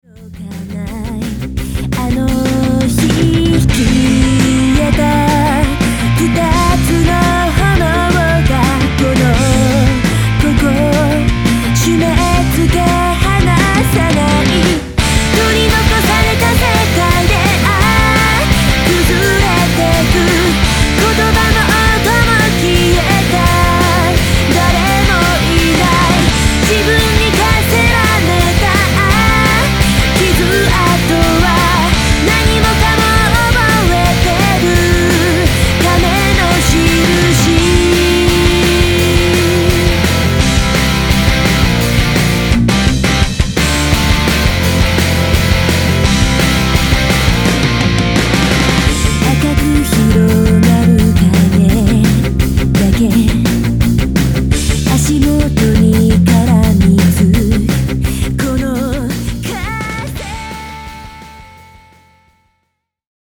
000*　全曲クロスフェードデモ
【lyrics/vocal】
【guest A.guitar&E.guitar : track06】
【recording studio】